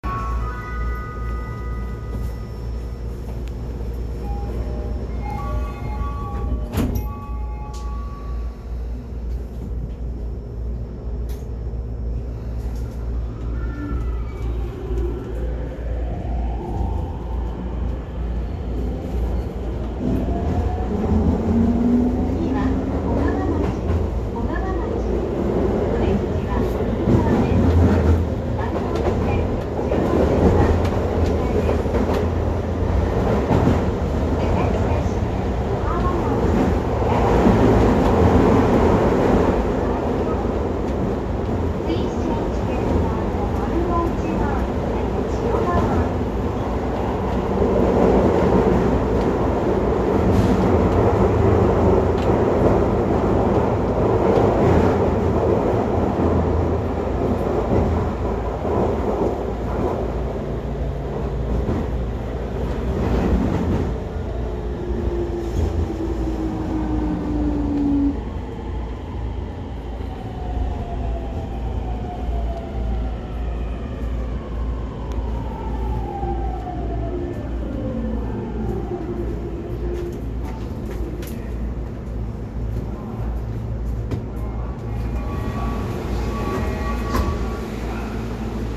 ・10-300形（2次車まで）走行音
【新宿線】船堀→本八幡
線路の幅を都営新宿線に合わせただけで、基本的にJR東日本のE231系(500番台etc)と全く同じ三菱IGBTです。ドアチャイムもJRタイプですが、若干音程が低くなっています。